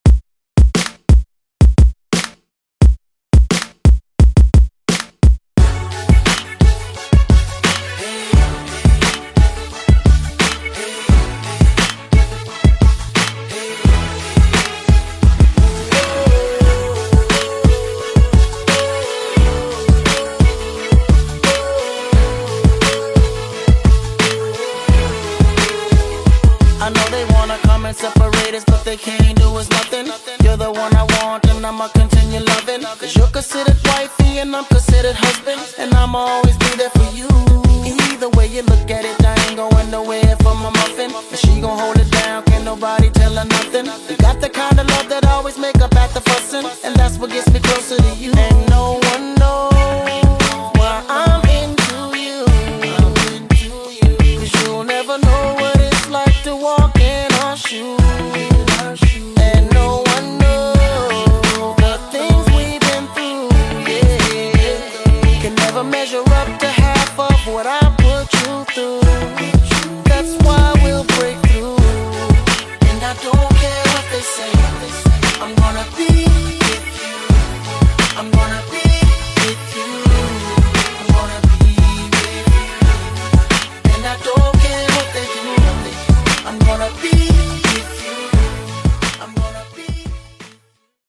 Genres: BOOTLEG , MASHUPS
Clean BPM: 127 Time